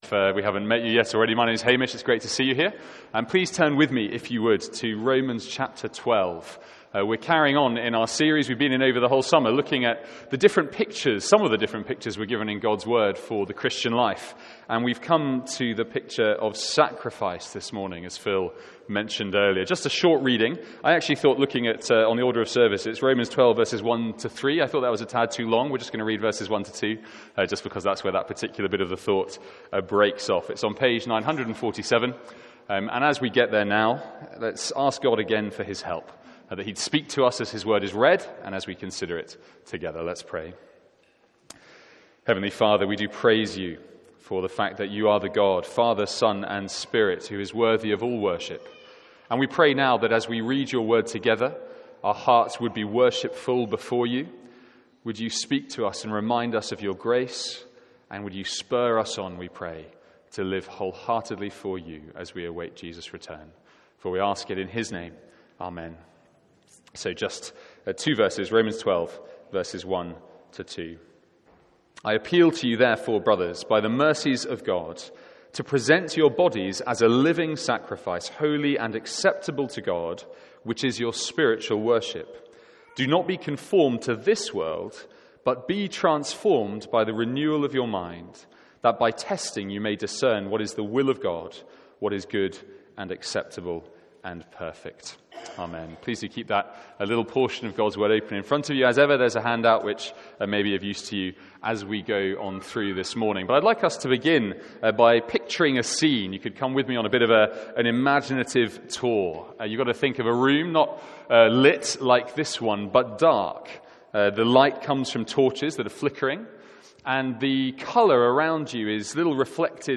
Sermons | St Andrews Free Church
From our morning series in the Christian Identity.